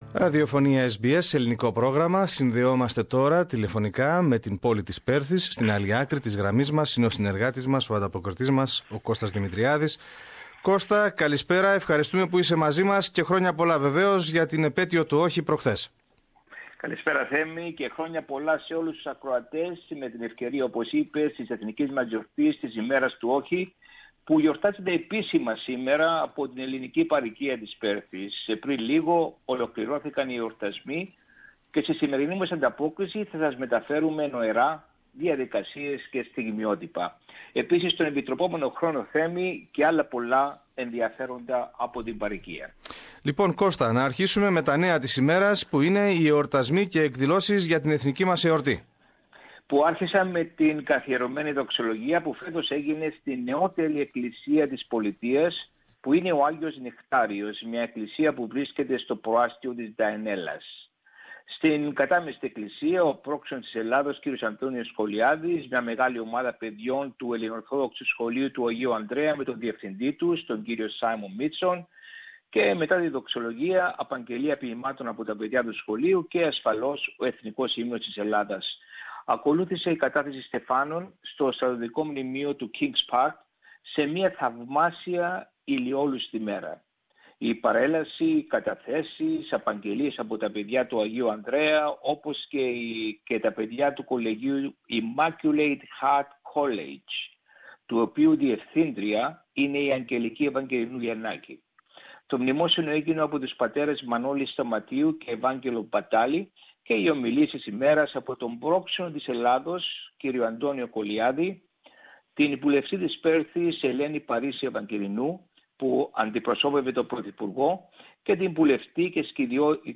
Ακολουθούν στιγμιότυπα από την τελετή της κατάθεσης στεφάνων στο στρατιωτικό μνημείο του Kings Park στην Πέρθη Δυτικής Αυστραλίας Share